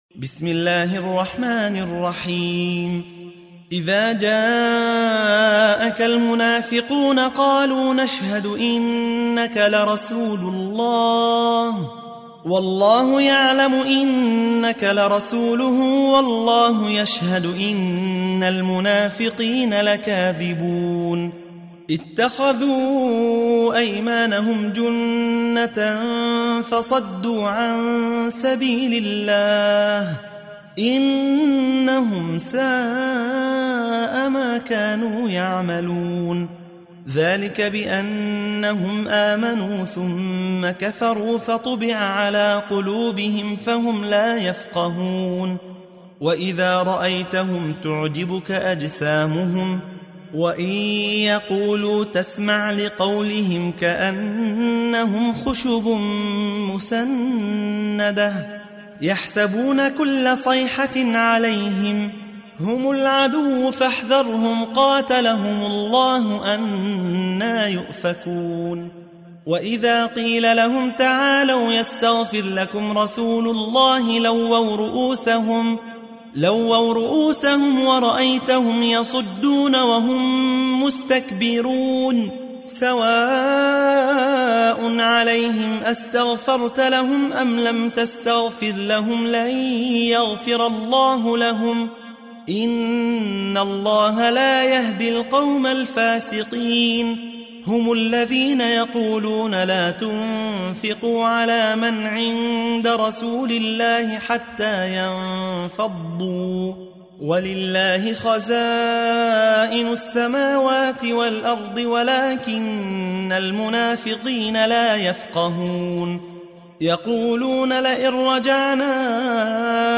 تحميل : 63. سورة المنافقون / القارئ يحيى حوا / القرآن الكريم / موقع يا حسين